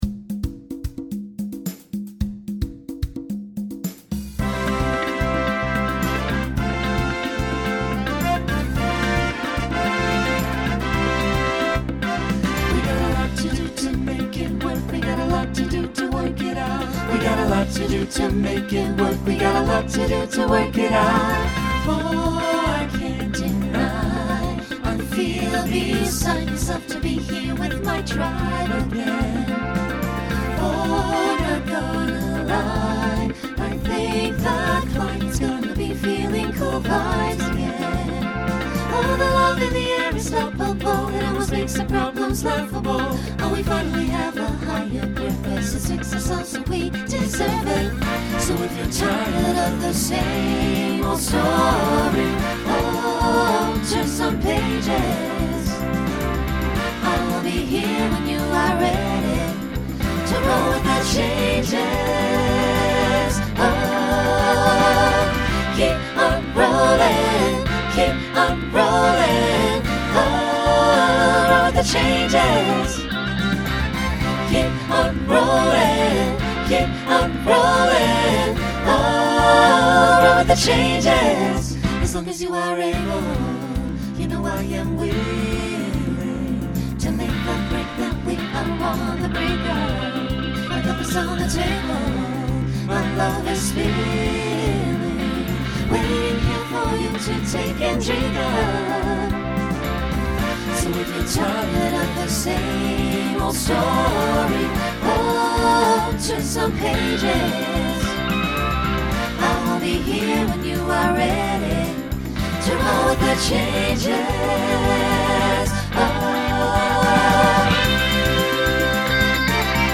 Broadway/Film , Rock Instrumental combo
Mid-tempo Voicing SATB